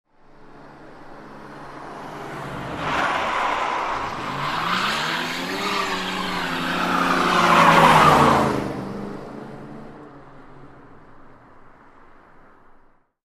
Здесь собраны реалистичные записи скольжения автомобилей по разным поверхностям: от асфальтовых треков до сельских грунтовок.
Шум скользящего автомобиля